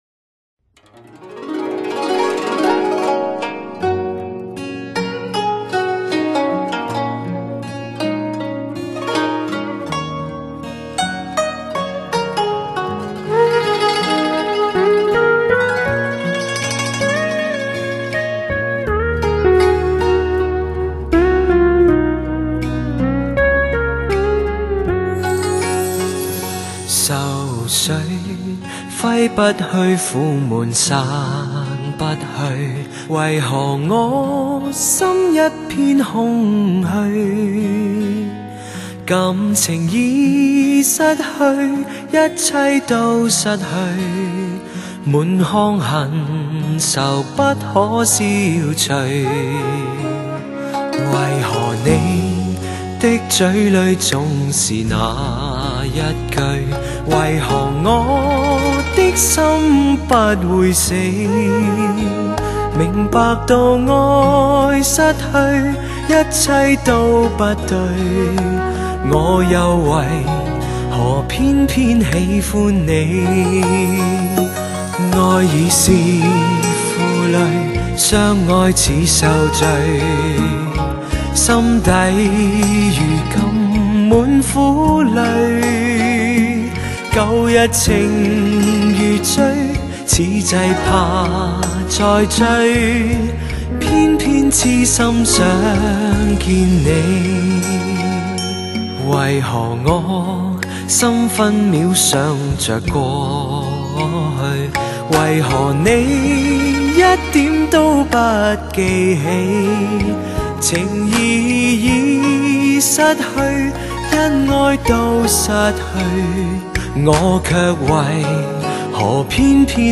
气韵之声 余音绕粱
特有的男中音音质兼具流行音乐的忧郁抒情、古典音乐的神圣古朴和歌剧的深沉有力。